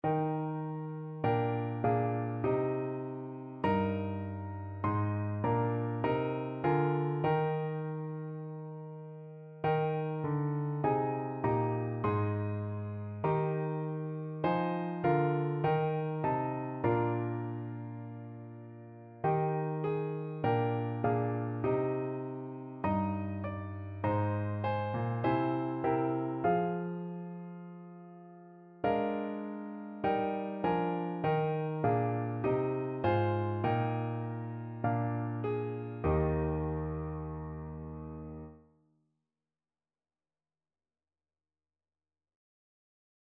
Piano Four Hands (Piano Duet) version
4/4 (View more 4/4 Music)
Classical (View more Classical Piano Duet Music)